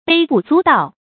卑不足道 注音： ㄅㄟ ㄅㄨˋ ㄗㄨˊ ㄉㄠˋ 讀音讀法： 意思解釋： 道：說，講。指卑下得不值一提 出處典故： 紹劇《龍虎斗》第三場：「此身 卑不足道 ，惟恐奸賊心存異志，宋室難安。」